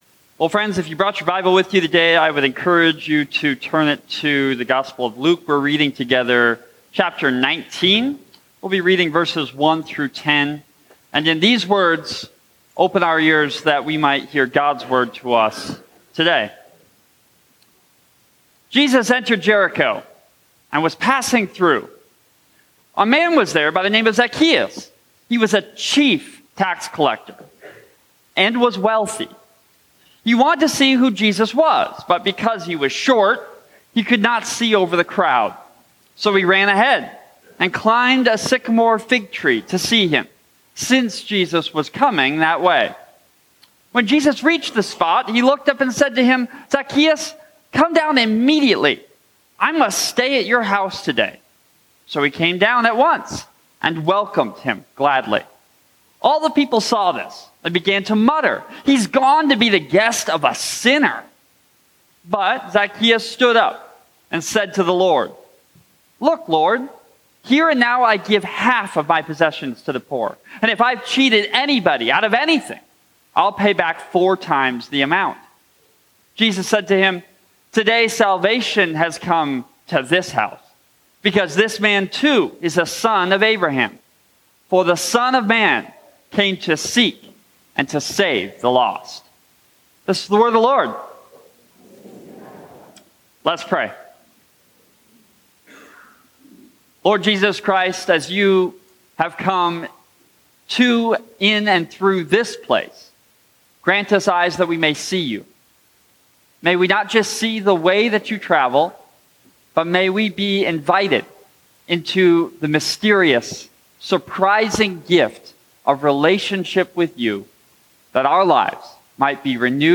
Sermons & Bulletins